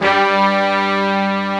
Index of /90_sSampleCDs/AKAI S-Series CD-ROM Sound Library VOL-1/BRASS SECT#1